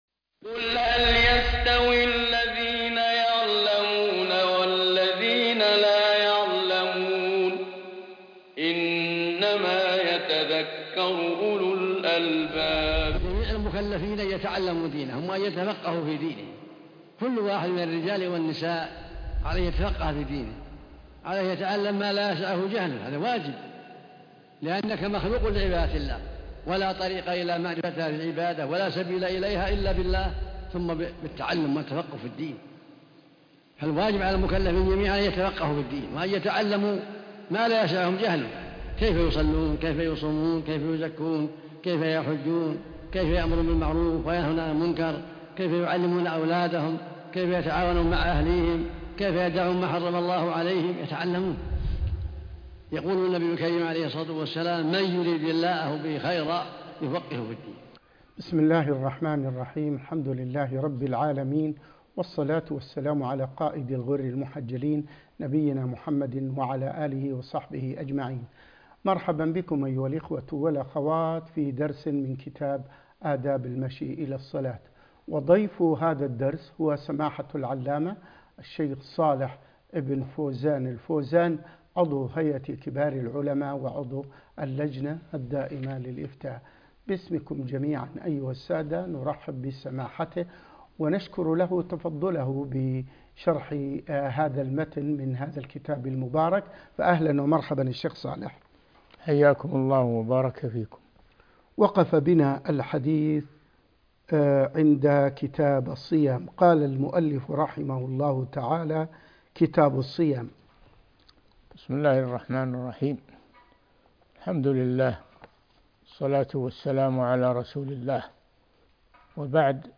الدرس (6) آداب المشي إلى الصلاة (6) - الشيخ صالح بن فوزان الفوازان